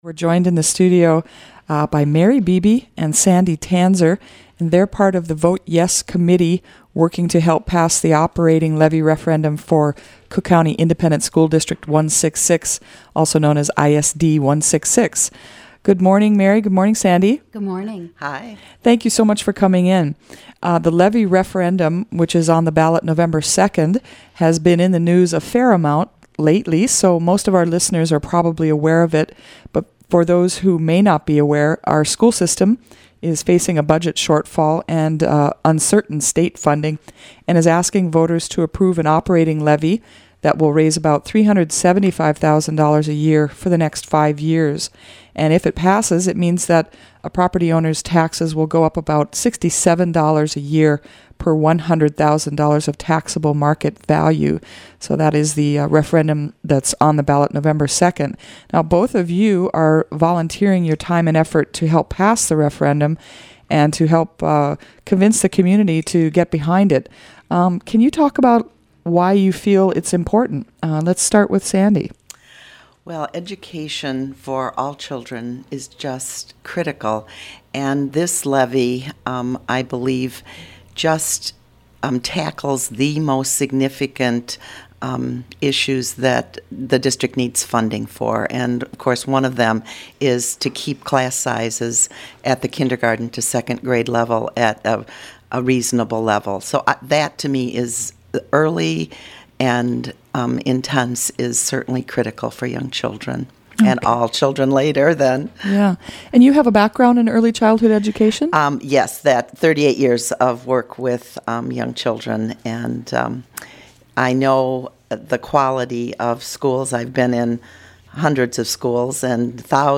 ISD 166 levy referendum interviews: pro and con